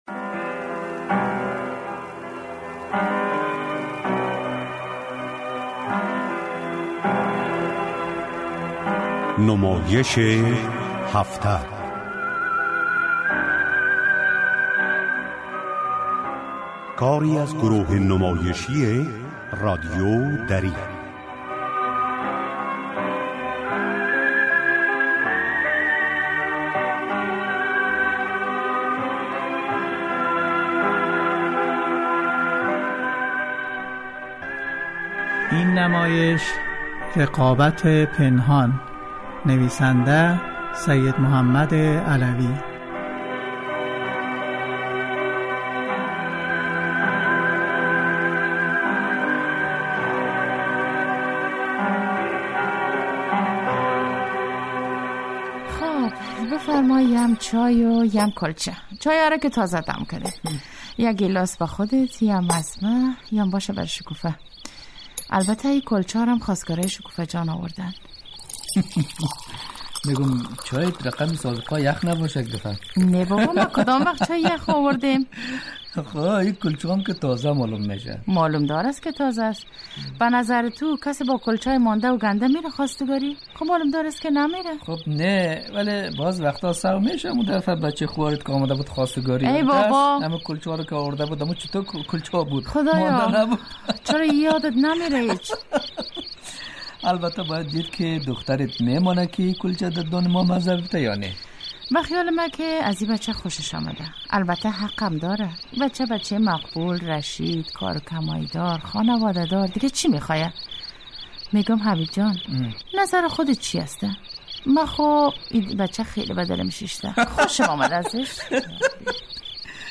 نمايش هفته : رقابت پنهان نويسنده